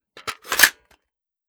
Foley / 5.56 M4 Rifle - Magazine Loading 001.wav